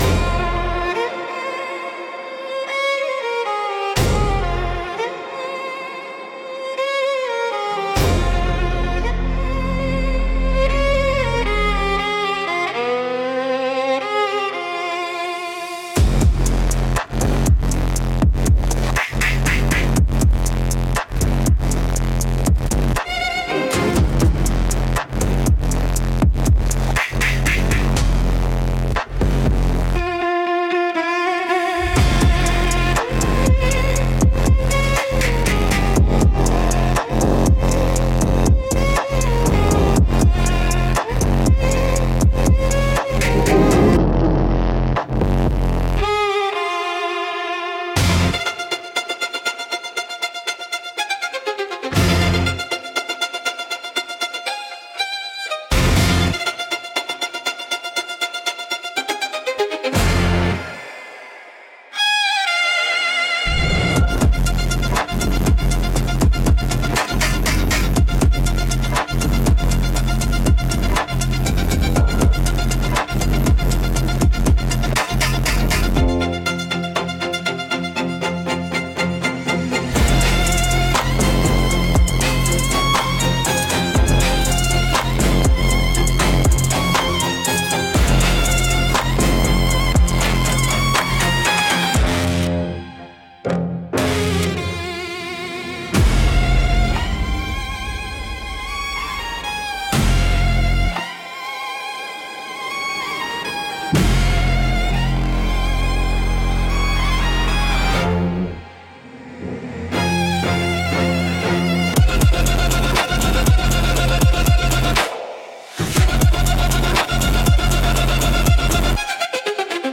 Instrumental - Southern Gothic x Dark Pop Fusion